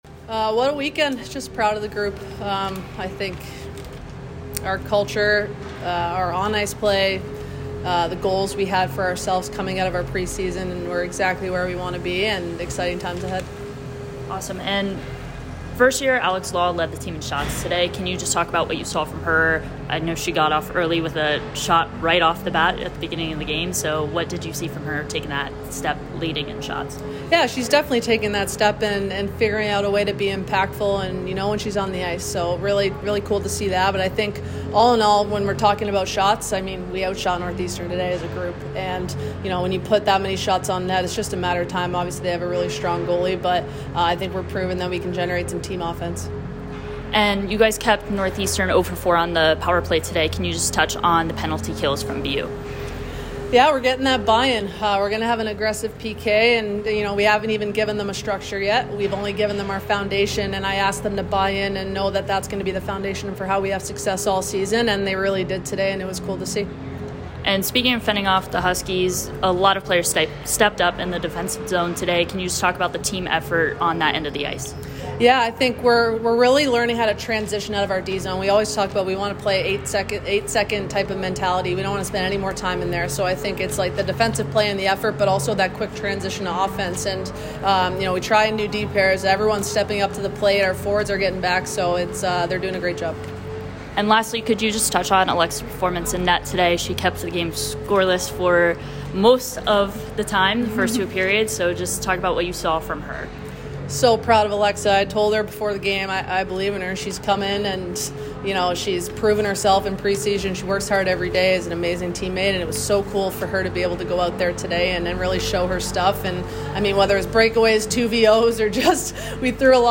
Women's Ice Hockey / Northeastern Postgame Interview (10-7-23)